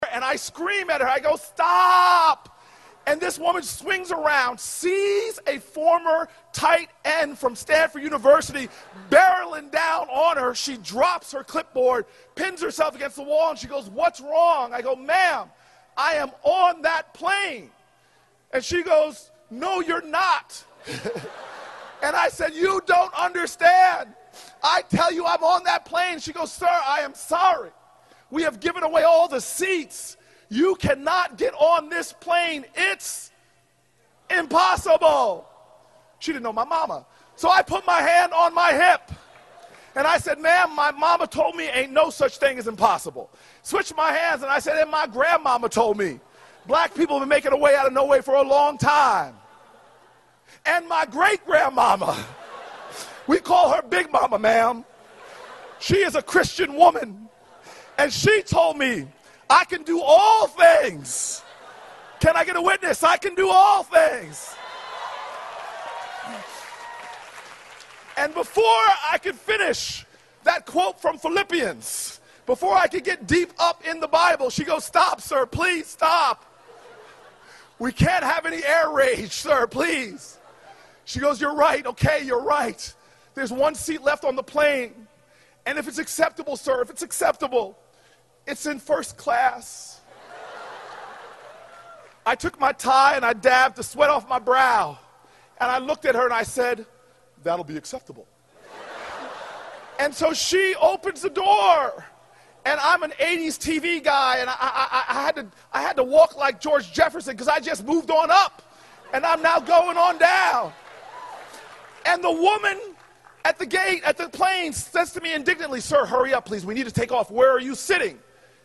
公众人物毕业演讲第442期:科里布克2013年耶鲁大学(10) 听力文件下载—在线英语听力室